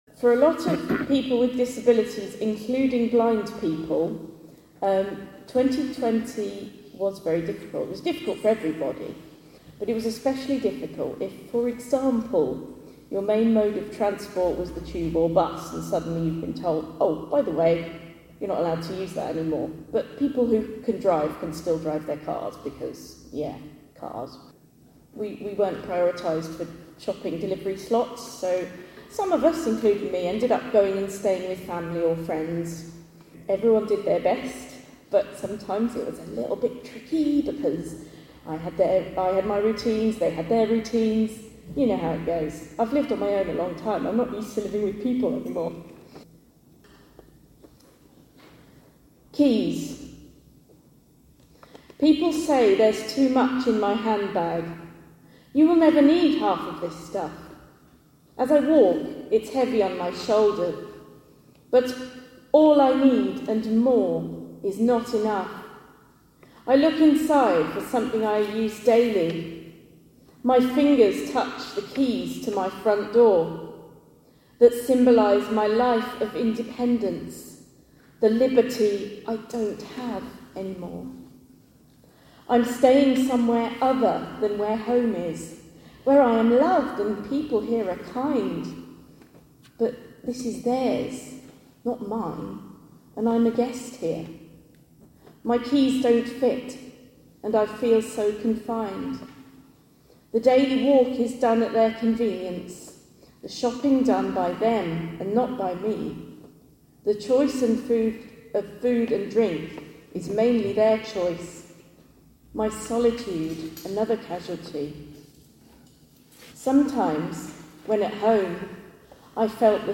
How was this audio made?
This is a very clear recording of this poem, made during a concert by the Inner Vision Orchestra on 16 March 2022.